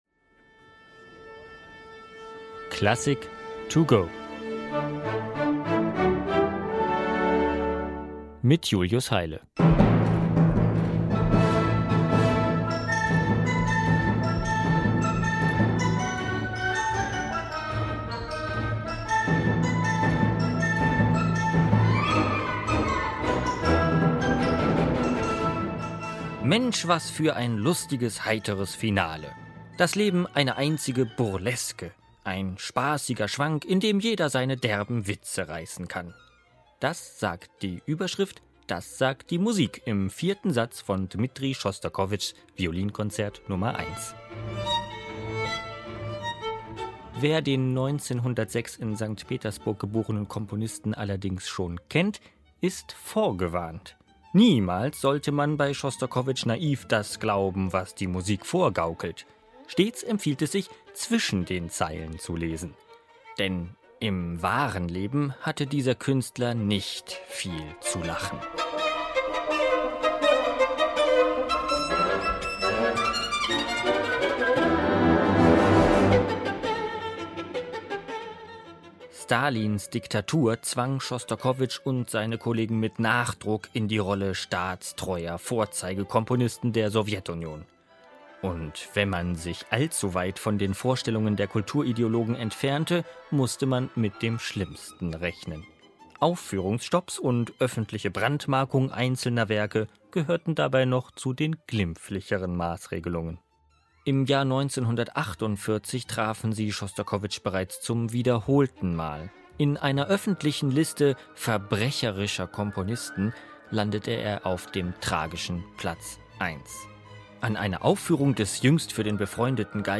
Werkeinführung für unterwegs: Bei dem russischen Komponisten